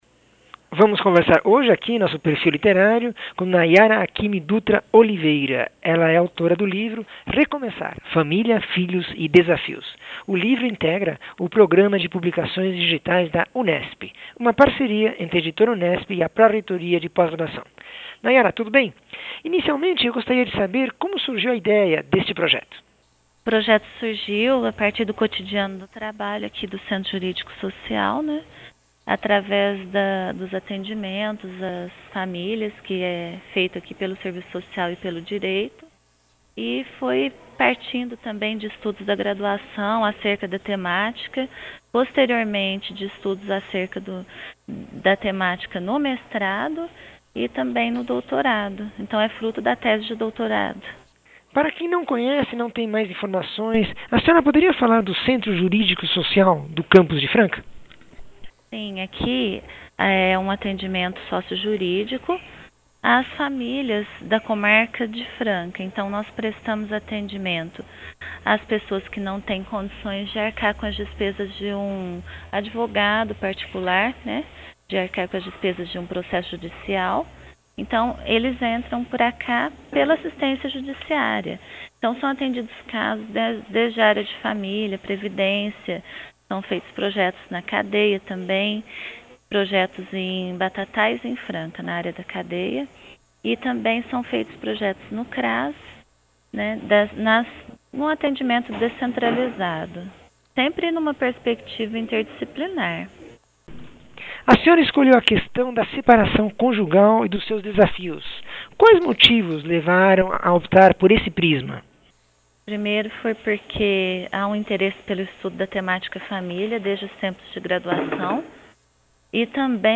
entrevista 658